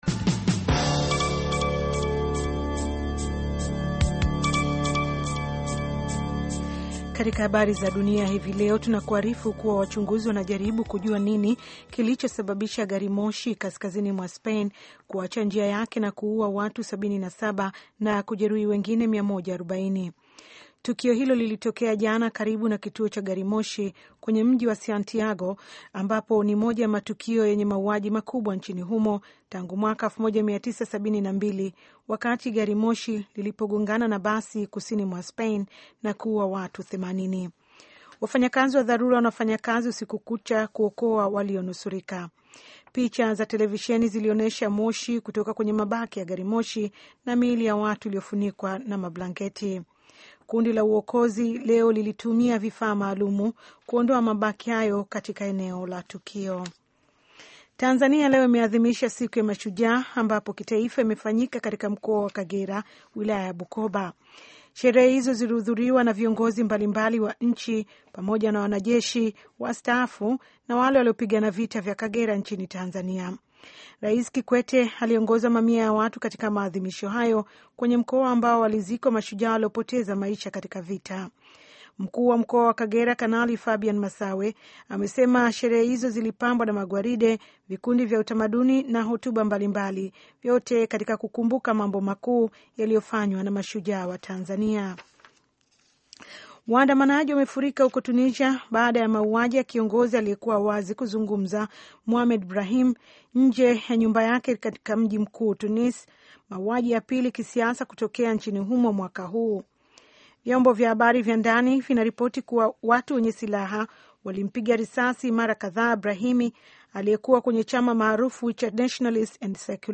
Taarifa ya Habari